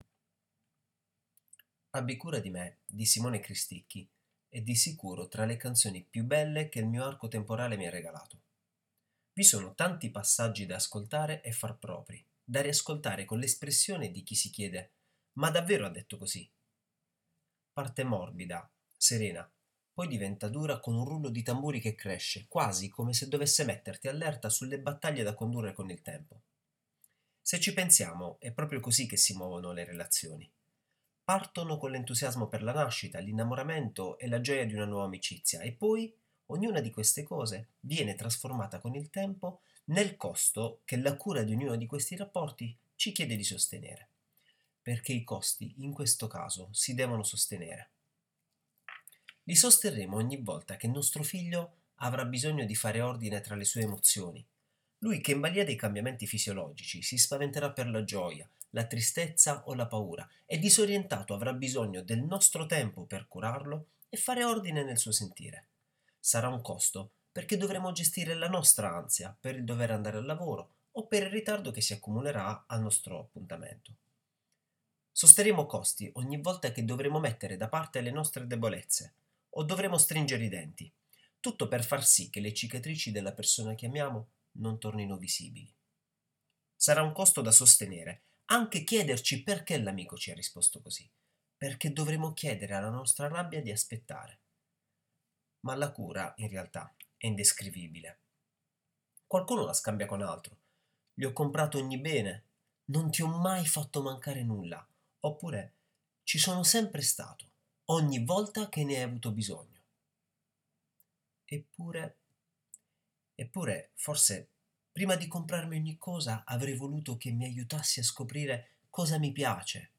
Clicca qui per ascoltare la lettura del post.